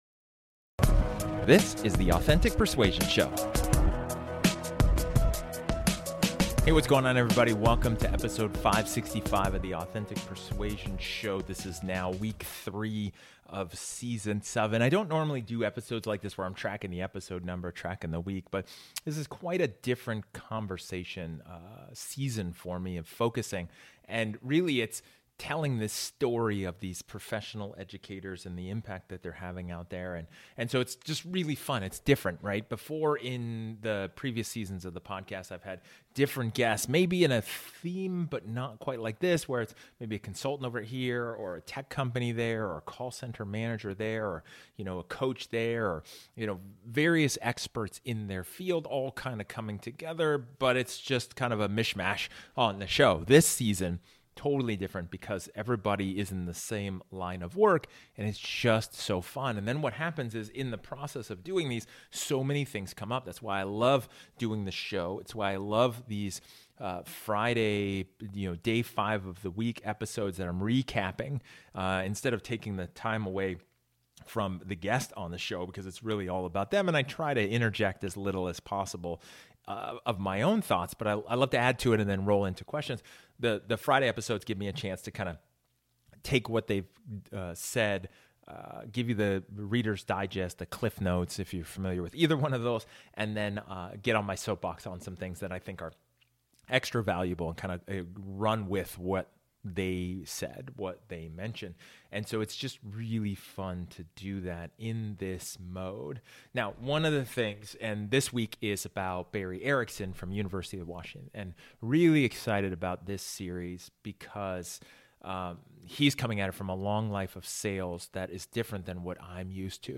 In this solo episode, I discuss the difference between manipulation and persuasion.